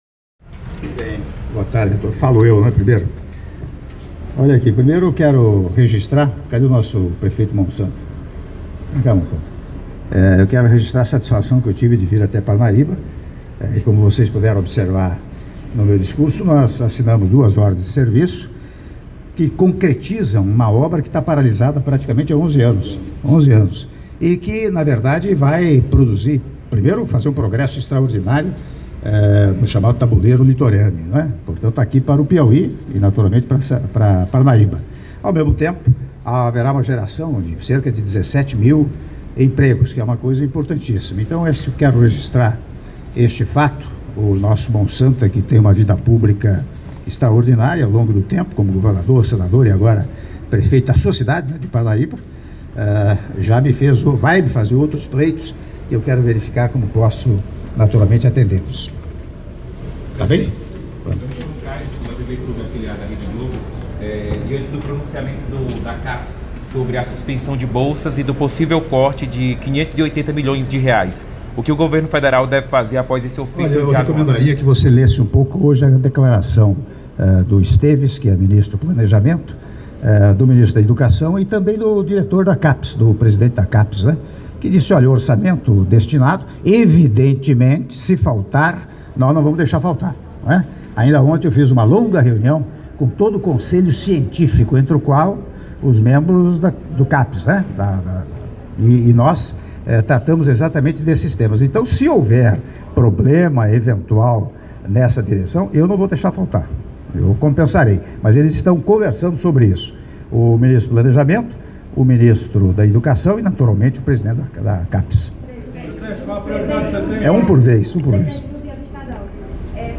Áudio da Entrevista coletiva concedida pelo Presidente da República, Michel Temer - Parnaíba/PI (04min04s)